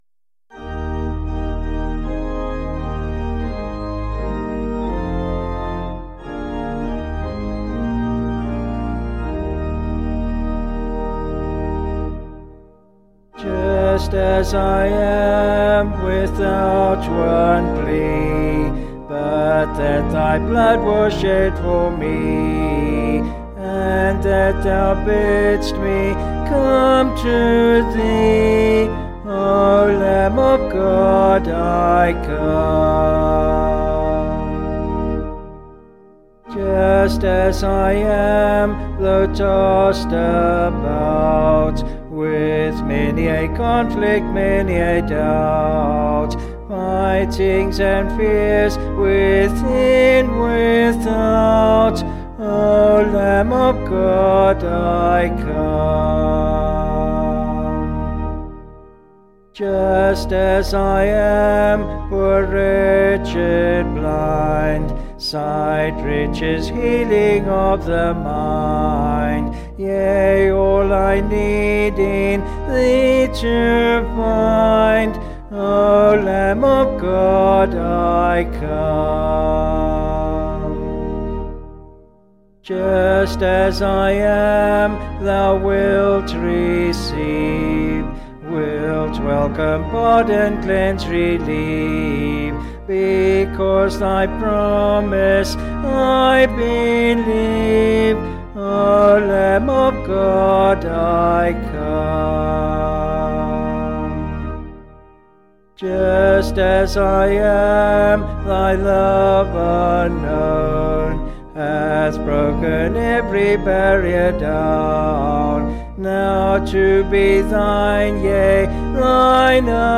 Vocals and Organ
264kb Sung Lyrics